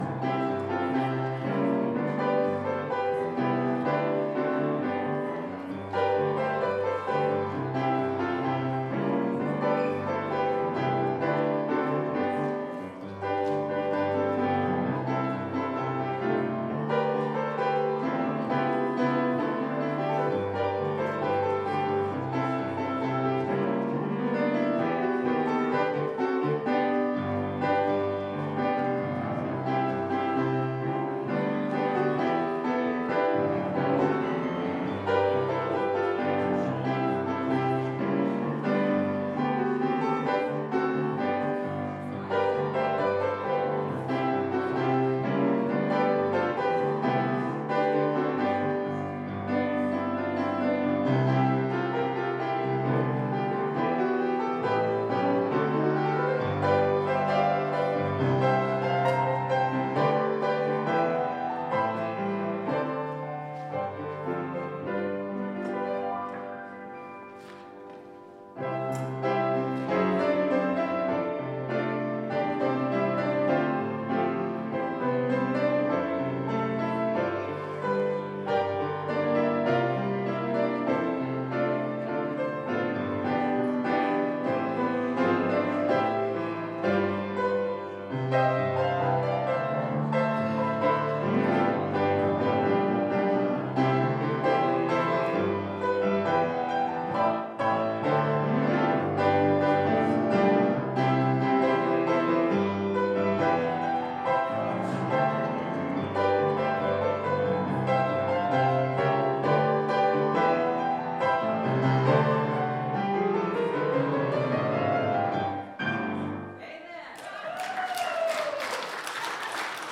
Lenten Service - First Lutheran Church